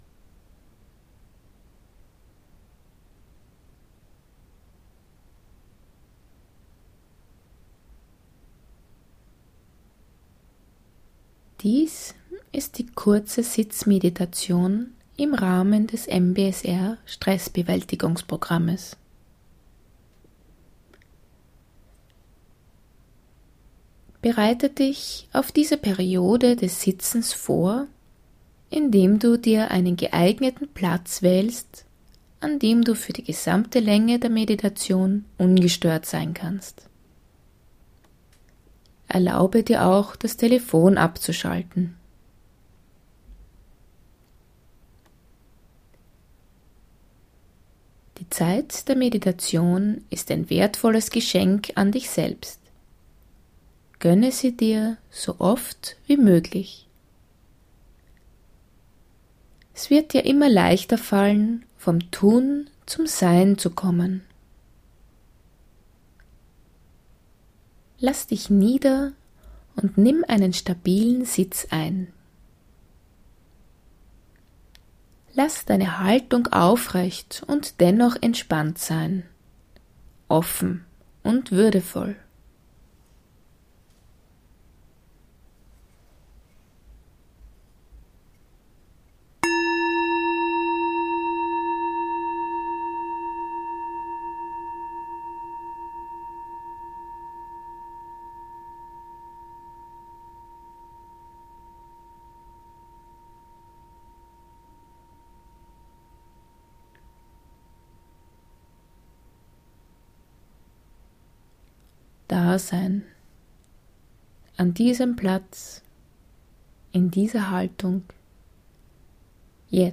Kurze Sitzmeditation 20 min